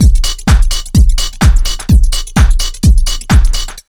127BEAT4 2-L.wav